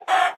chicken_hurt2.ogg